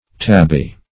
Tabby \Tab"by\ (t[a^]b"b[y^]), n.; pl. Tabbies